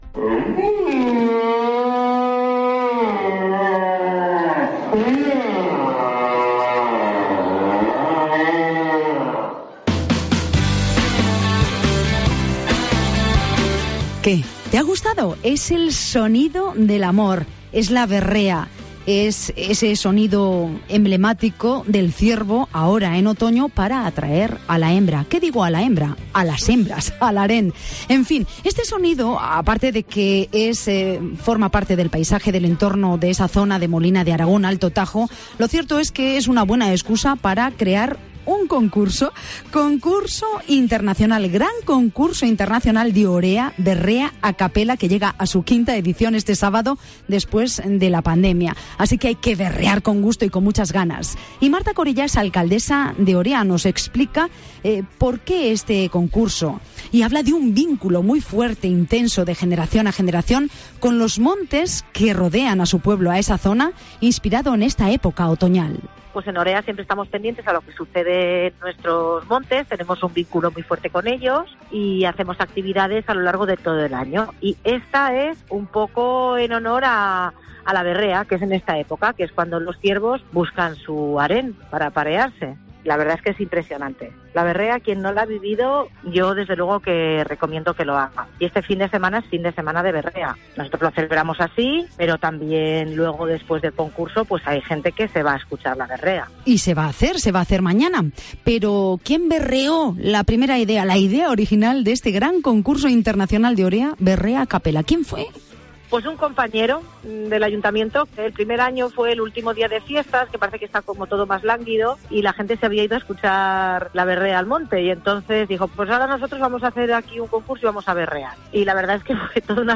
Así lo ha señalado en Mediodía COPE Guadalajara, la alcaldesa de Orea, Marta Corella, explicando que "se hace detrás de un fotocol, donde hay un ciervo berreando; se hace una cena popular y es el público el que decide con sus aplausos quien pasa a la siguiente fase, quién gana, vamos que quien quiere y se atreve sube y berrea y si se gana el afecto del público y le aplauden, va pasando".